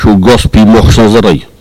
parole, oralité
Catégorie Locution